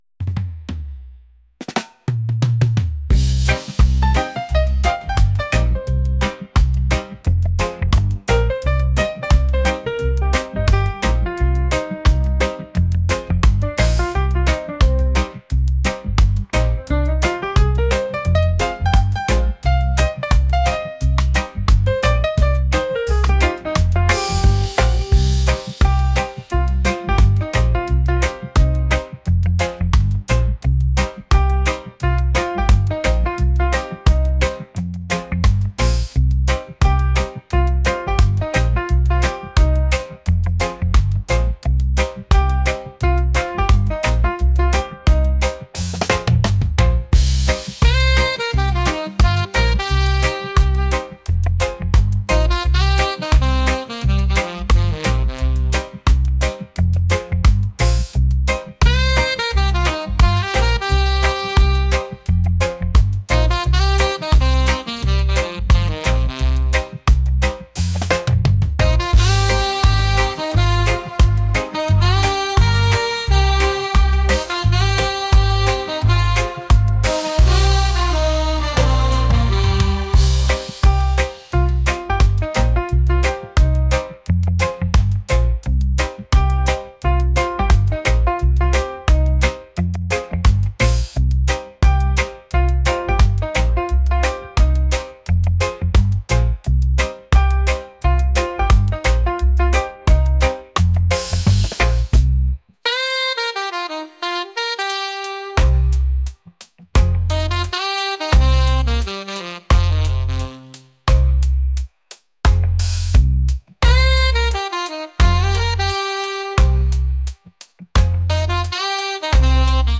reggae | soul & rnb | lofi & chill beats